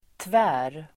Uttal: [tvä:r]